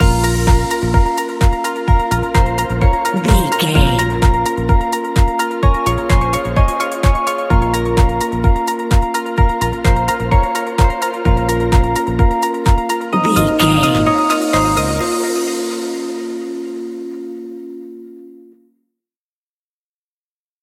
Aeolian/Minor
B♭
groovy
uplifting
driving
energetic
drum machine
synthesiser
bass guitar
funky house
upbeat
funky guitar
clavinet